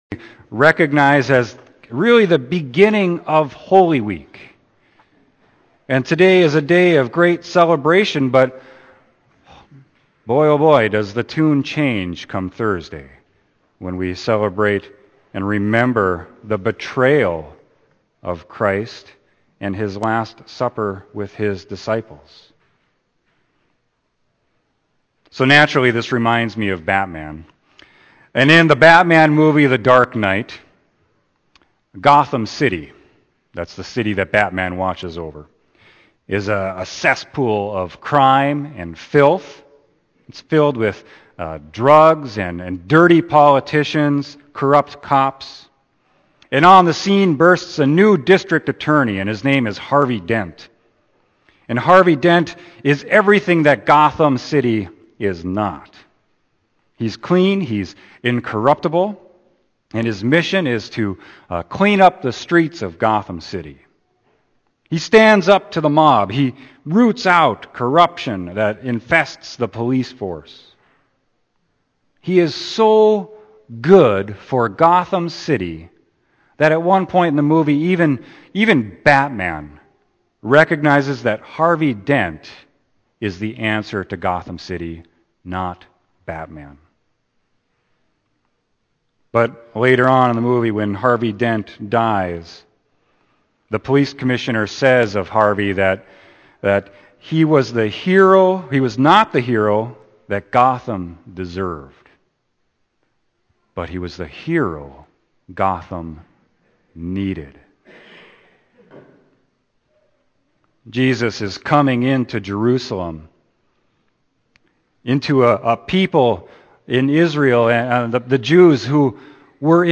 Sermon: John 12.9-19